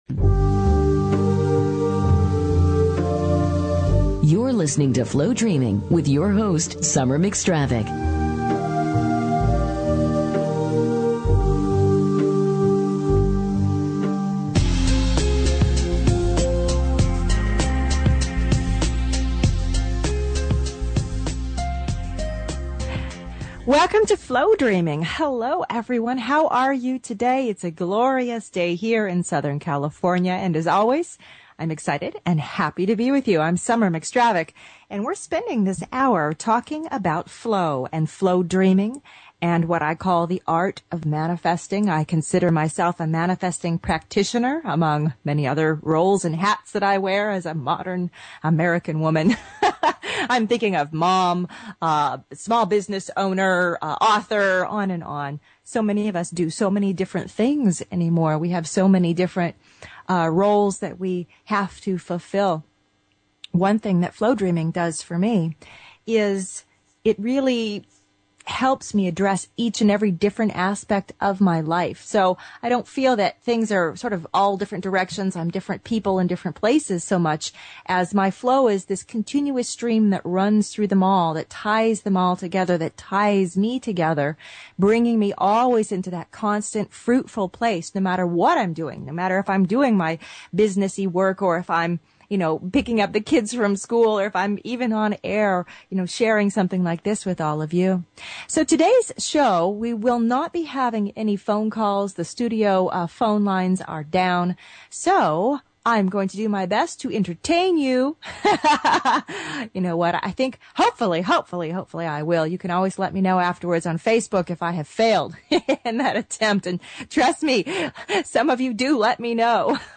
Talk Show Episode, Audio Podcast, Flowdreaming and Courtesy of BBS Radio on , show guests , about , categorized as